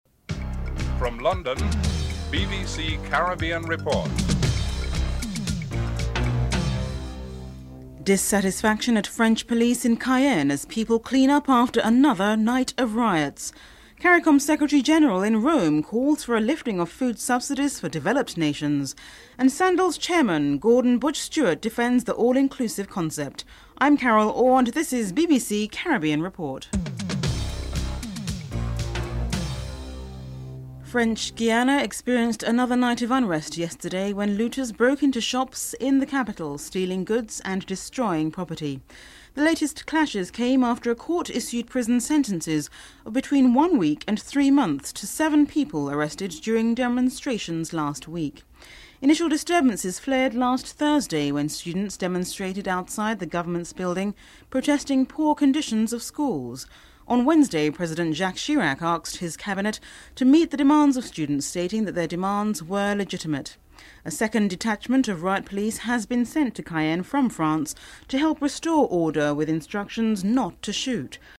1. Headlines (00:00-00:31)
Foreign Minister Billie Miller is interviewed (08:08-09:34)
Brian Lara is interviewed (13:08-15:25)